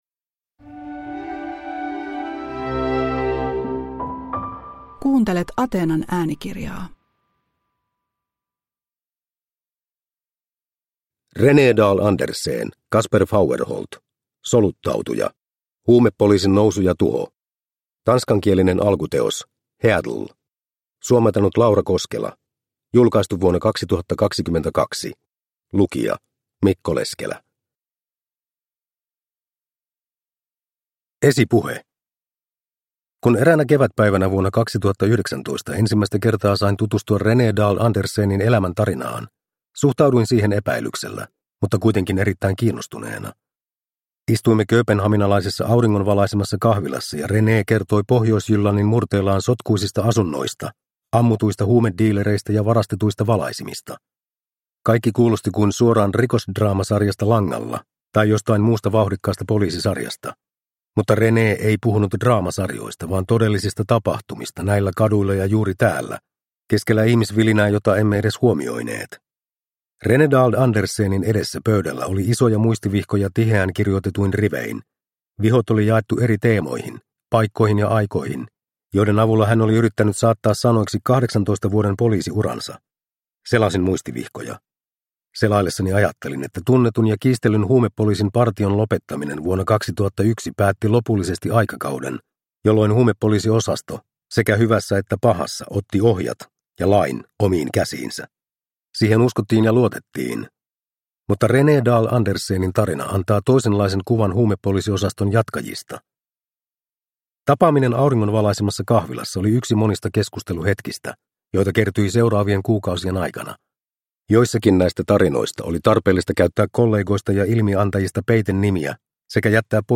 Soluttautuja – Ljudbok – Laddas ner